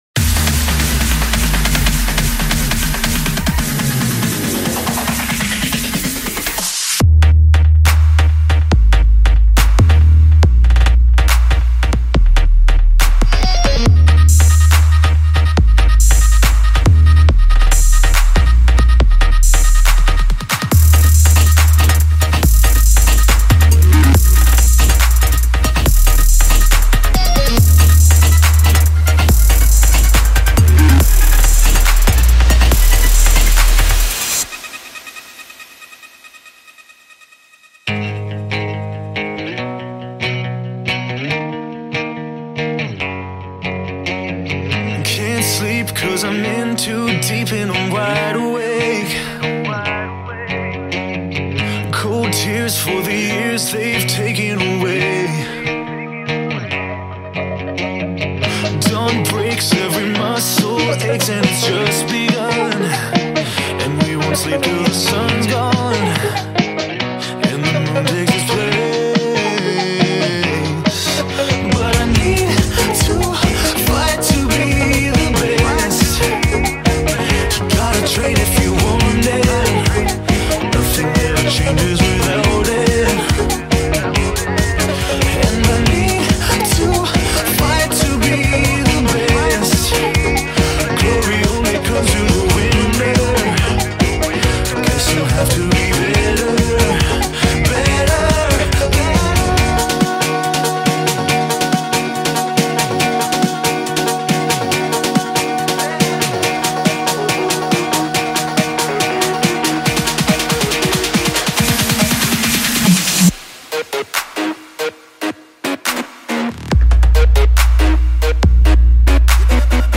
Edit/Remix